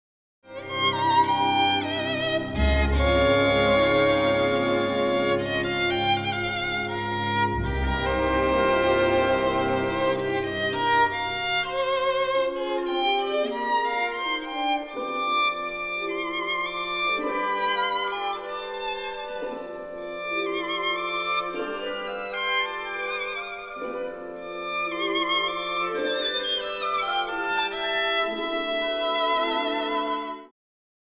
with its sumptuous solo violin